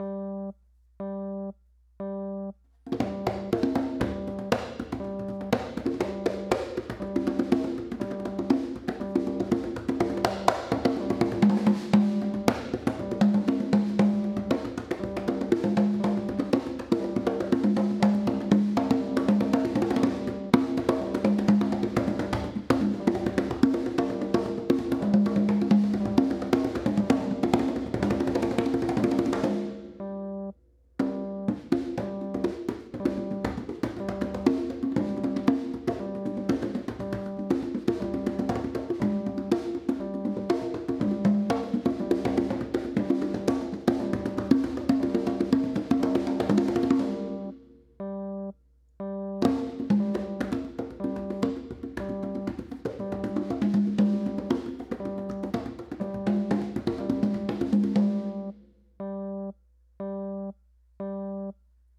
Percussion Improv